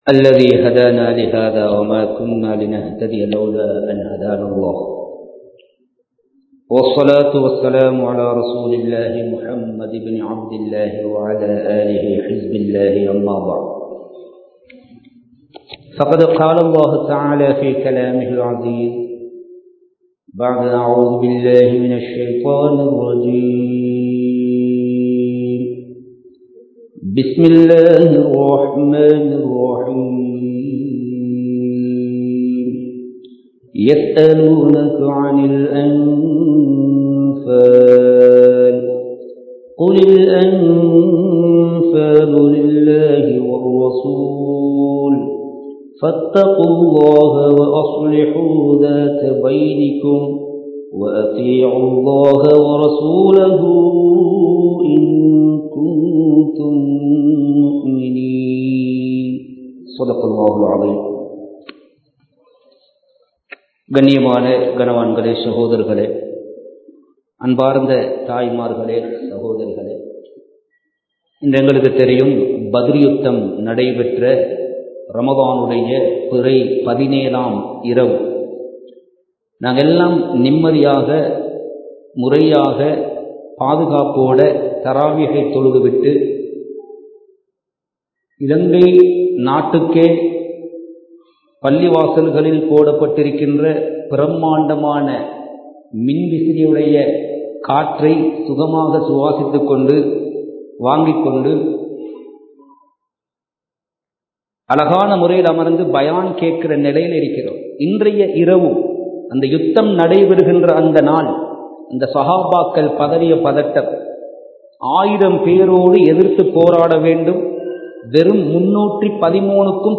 பத்ரும் அல்லாஹ்வின் உதவியும் | Audio Bayans | All Ceylon Muslim Youth Community | Addalaichenai
Kattukela Jumua Masjith